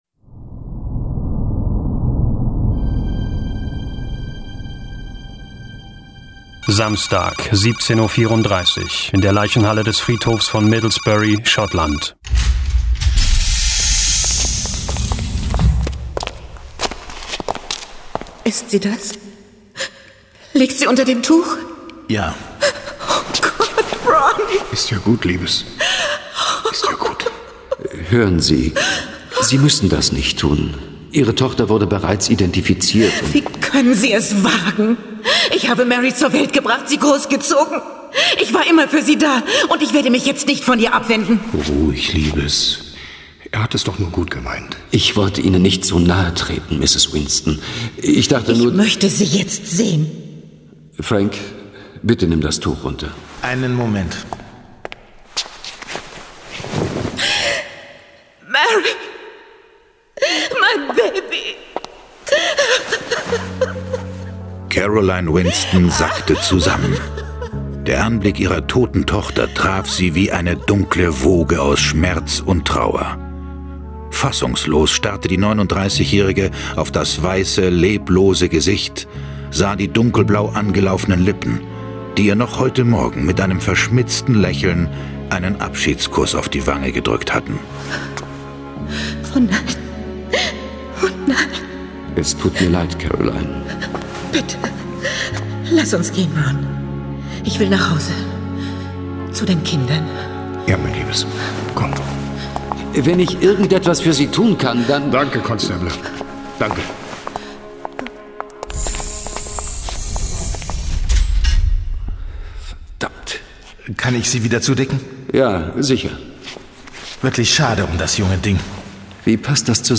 John Sinclair Classics - Folge 1 Der Anfang. Hörspiel.